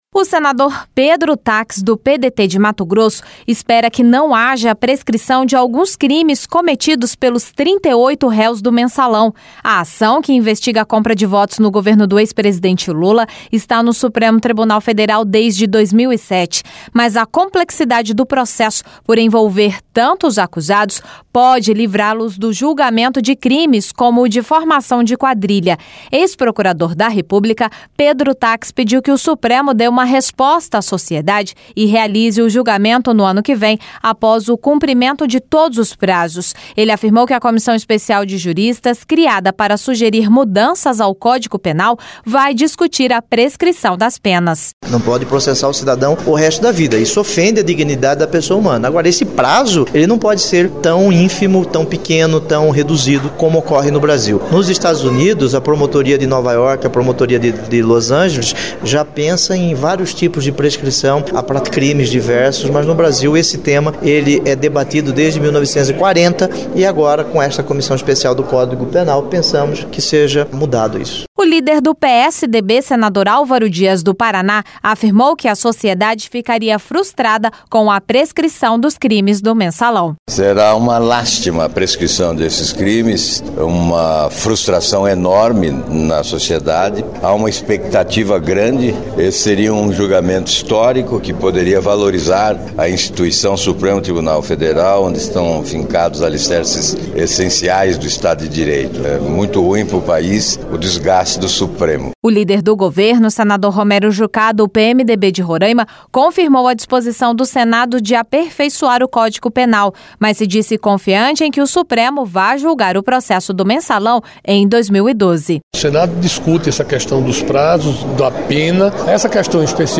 O senador Pedro Taques, do PDT de Mato Grosso, espera que não haja prescrição de alguns crimes cometidos pelos 38 réus do "mensalão".